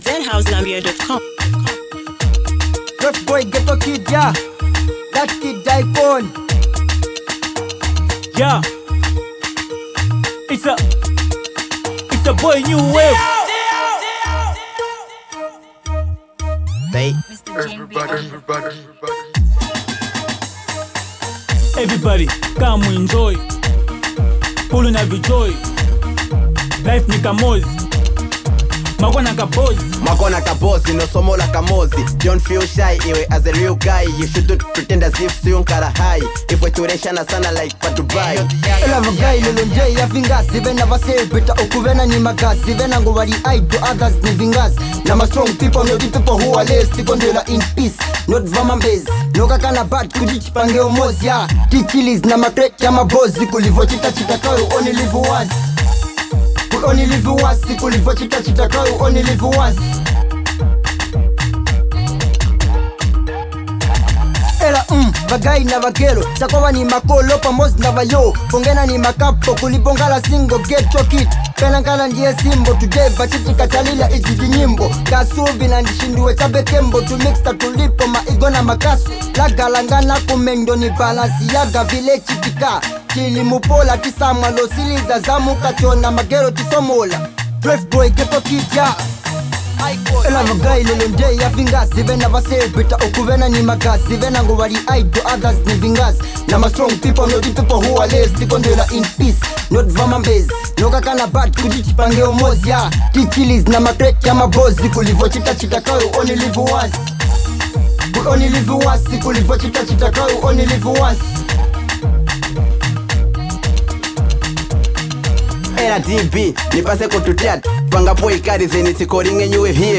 powerful anthem